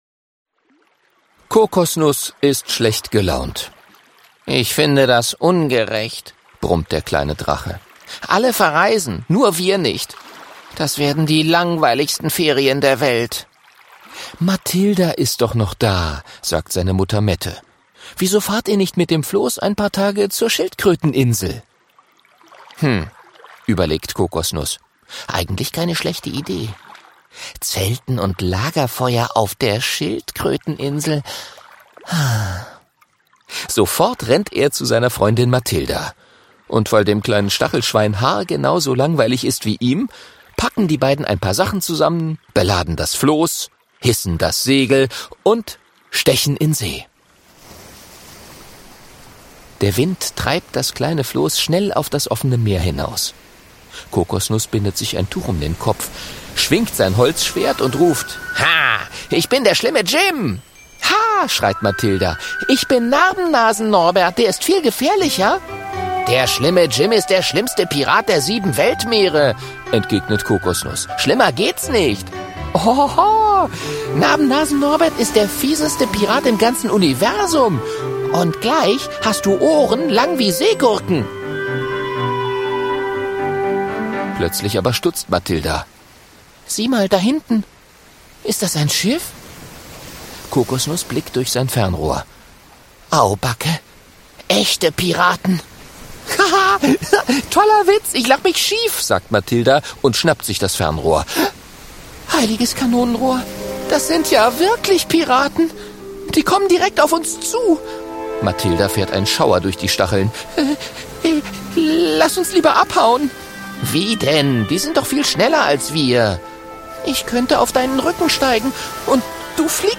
Ungekürzte Lesung, Inszenierung